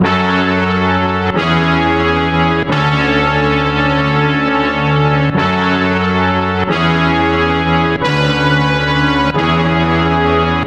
这是号角部分。 BPM大概是116
标签： 铜管